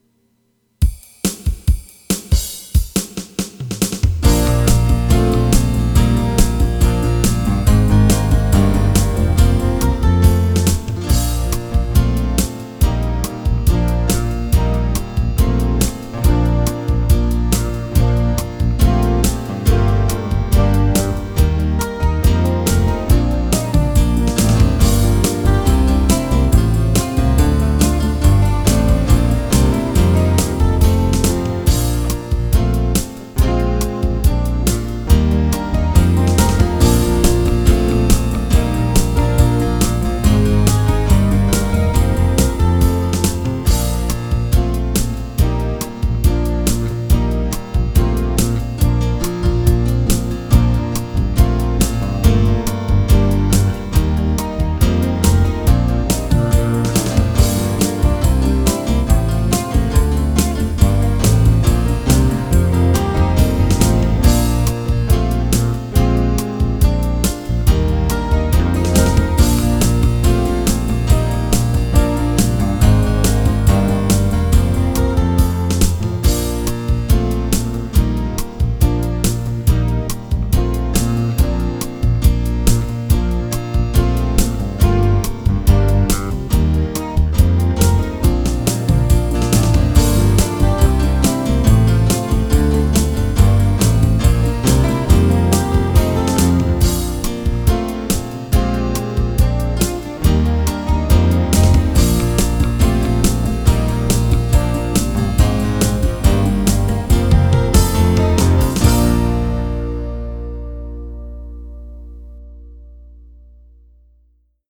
(studio track)
No Vocals